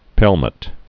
(pĕlmət)